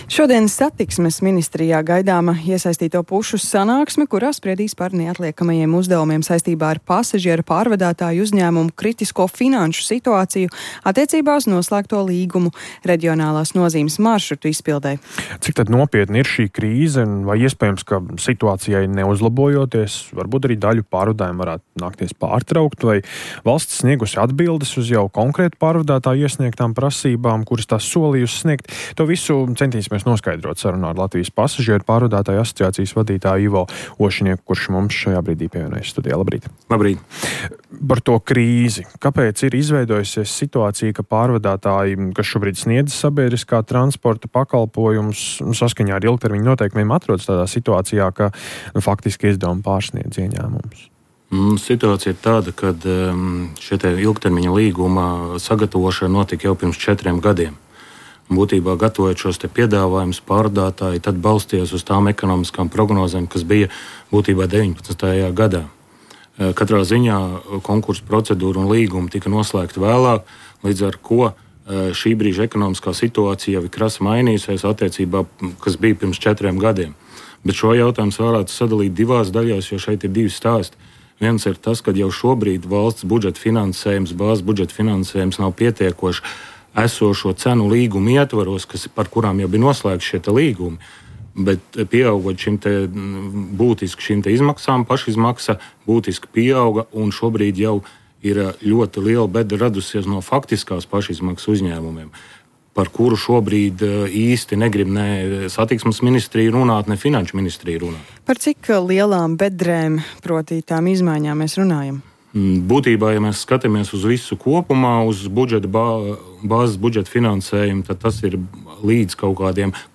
Rīta intervija